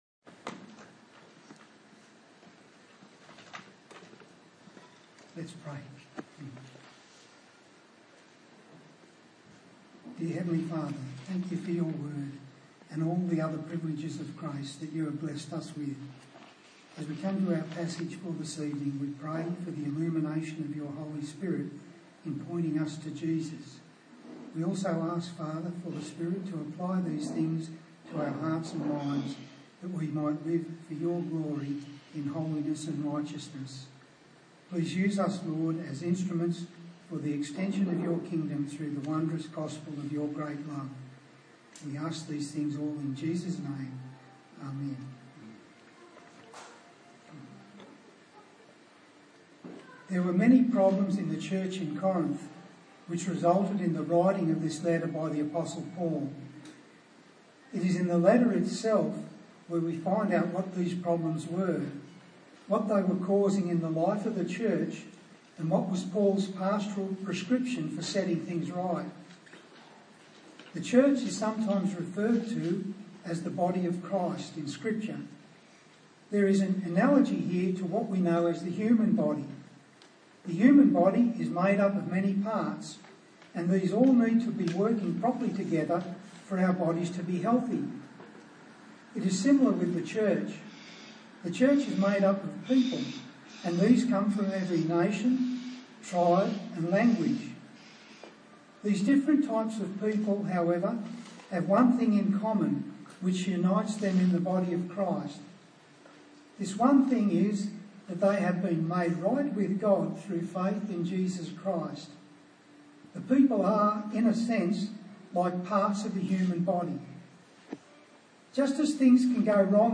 A sermon on the book of 1 Corinthians